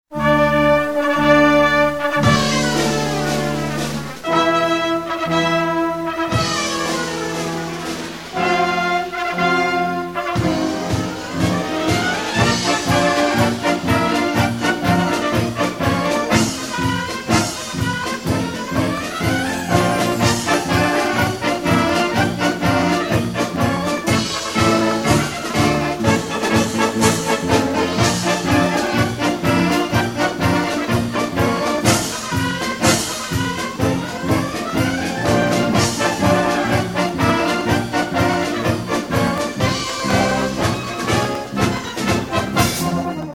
Konzert 2002 -Download-Bereich